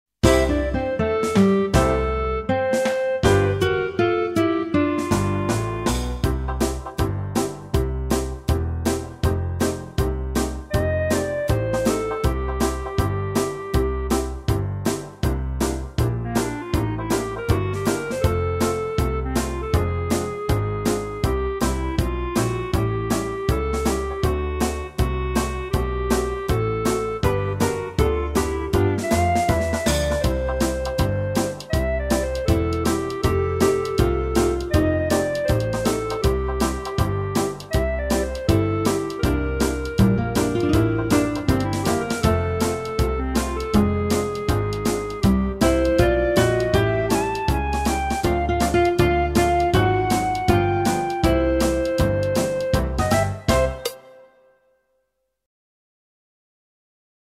Meespeel CD